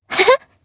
Sonia-Vox_Happy1_jp.wav